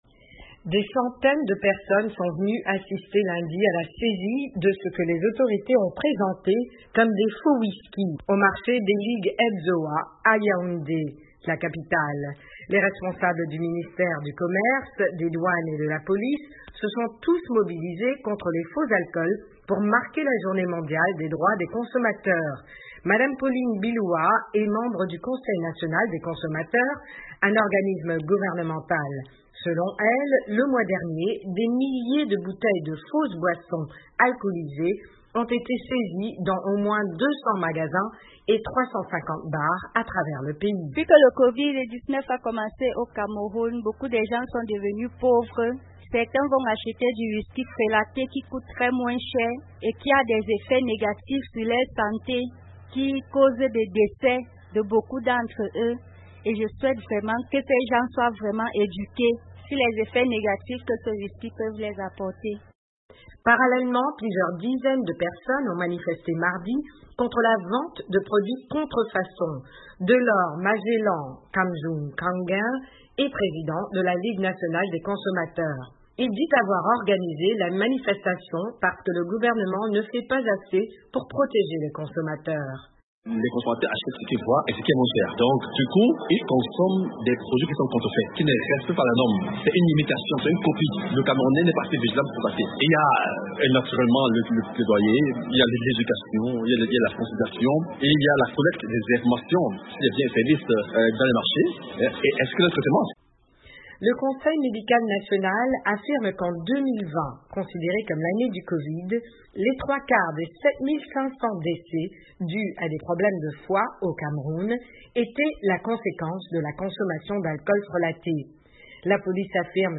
Au Cameroun les autorités affirment que la pandémie de coronavirus a entraîné une augmentation massive de la consommation d'alcool et des décès dus à l’alcool frelaté. Les associations de consommateurs ont manifesté lundi, affirmant que les autorités doivent faire plus pour lutter contre la contrefaçon de l’alcool et des médicaments. Un reportage